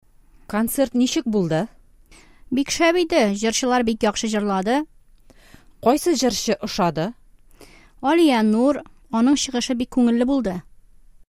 Диалог 3: Концерт турында тәэсирләр – Впечатления о концерте